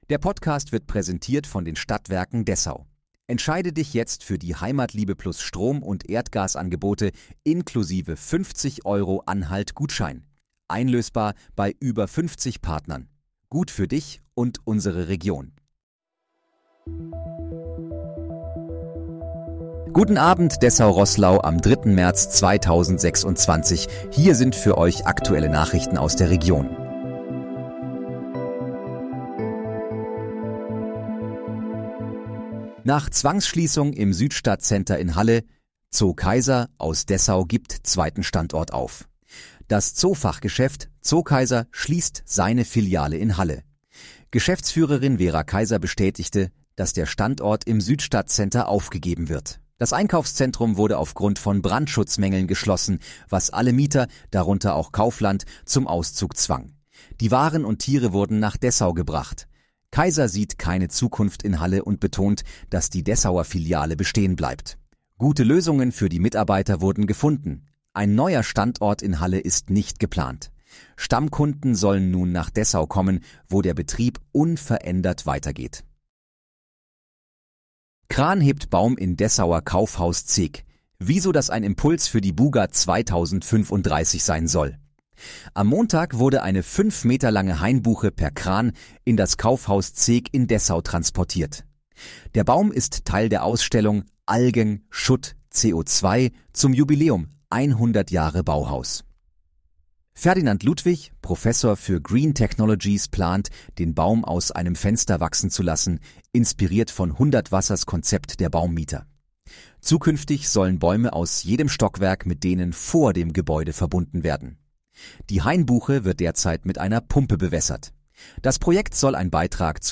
Guten Abend, Dessau-Roßlau: Aktuelle Nachrichten vom 03.03.2026, erstellt mit KI-Unterstützung